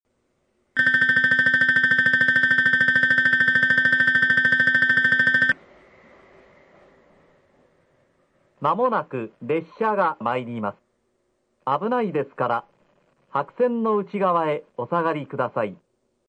スピーカー：ユニペックス（ワイドレンジ箱形）
音質：D
１.２番のりば 接近放送・男性（上り・博多方面） (80KB/16秒)
熊本支社に切り替わった瞬間、放送までも熊本県内標準放送へと変わります。ベルの音が異なります、筑肥線で多く使用されるベルです。